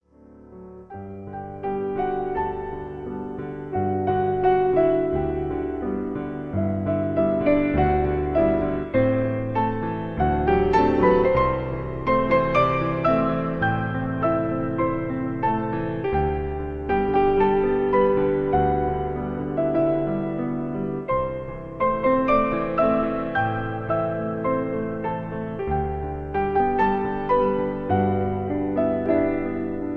Ein Lied an den Mond, romantisch, verträumt